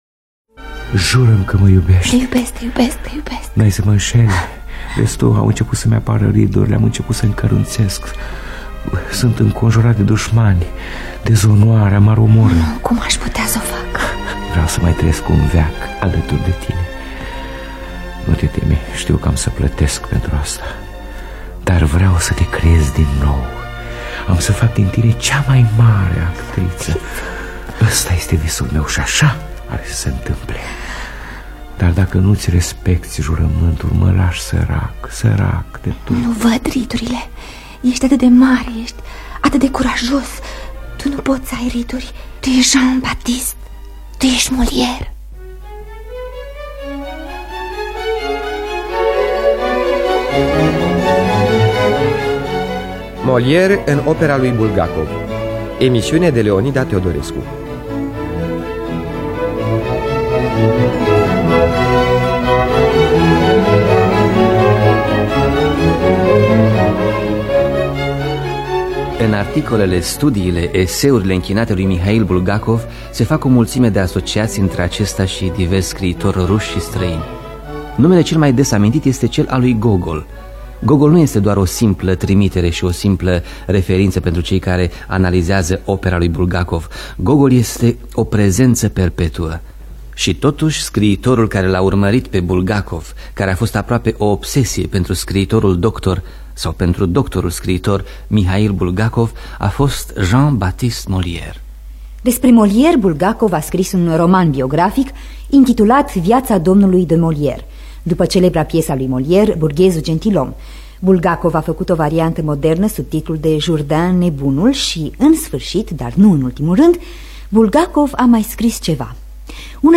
Biografii, memorii: Molière în opera lui Bulgakov.